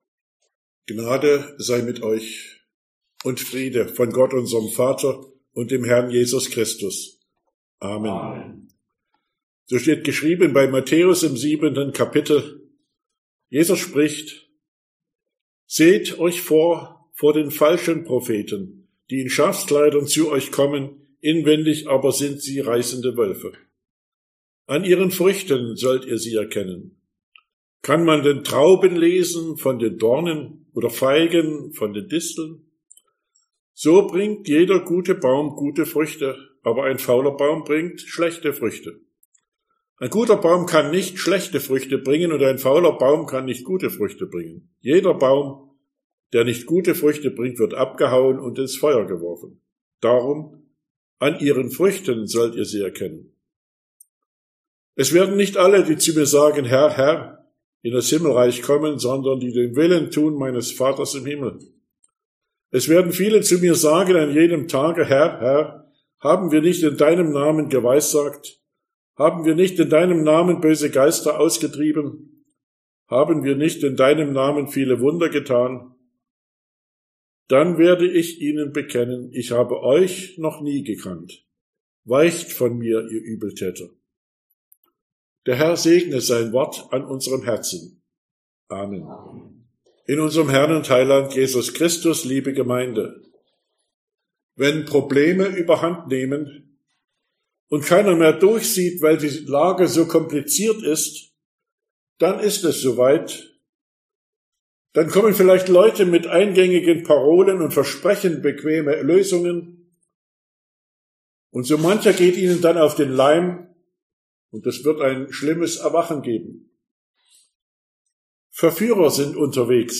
Sonntag nach Trinitatis Passage: Matthäus 7, 15-23 Verkündigungsart: Predigt « Trinitatisfest 2023 2.